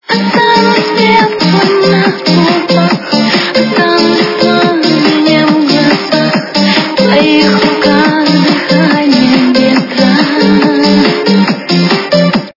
- Remix
русская эстрада
rmx качество понижено и присутствуют гудки.